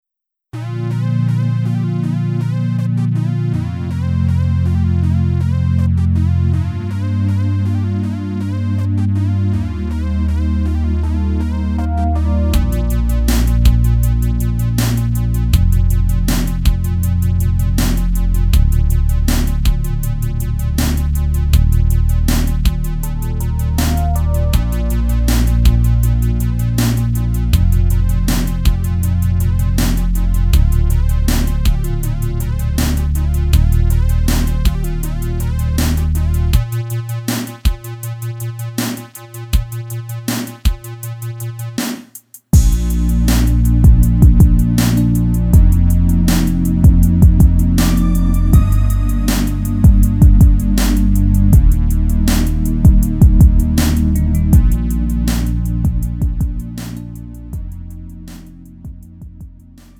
음정 -1키 4:21
장르 구분 Lite MR